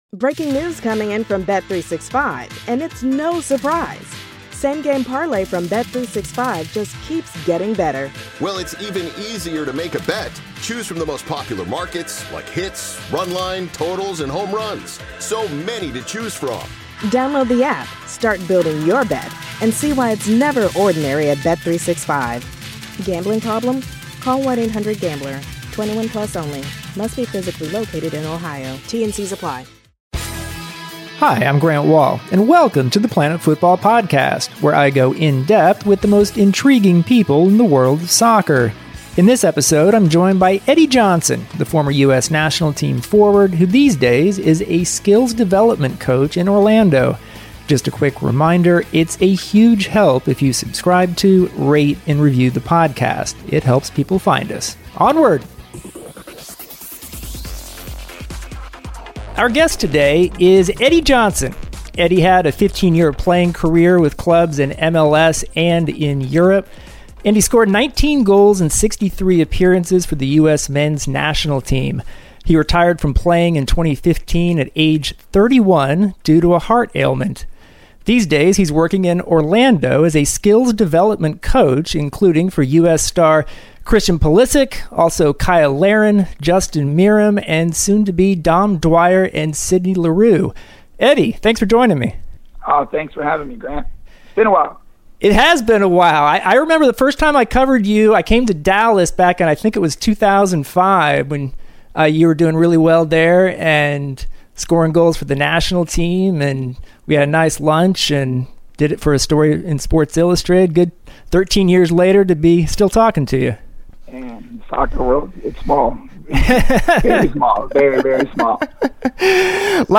Grant interviews Eddie Johnson, the former USMNT forward whose 15-year playing career ended in 2015 due to a heart condition.